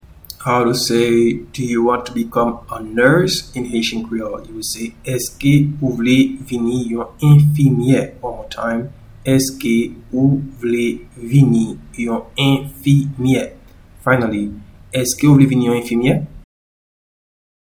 Pronunciation and Transcript:
Do-you-want-to-become-a-nurse-in-Haitian-Creole-Eske-ou-vle-vini-yon-enfimye.mp3